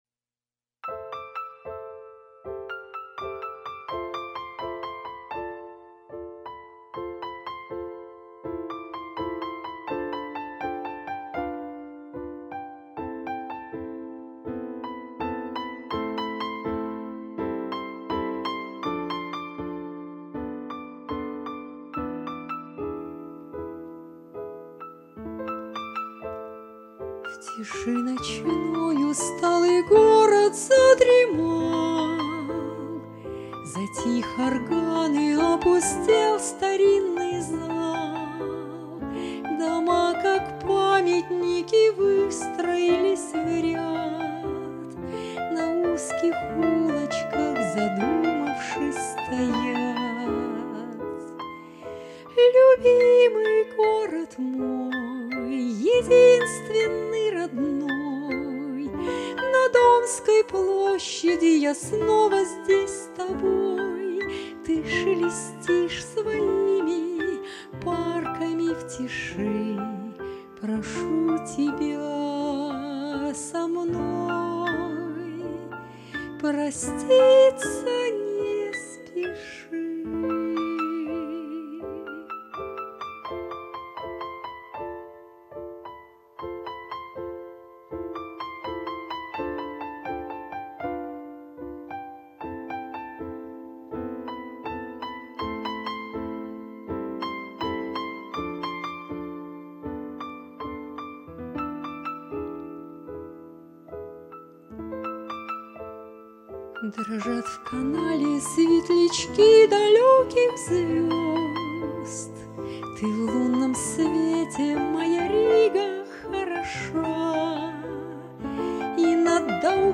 Арранжировка и соло на рояле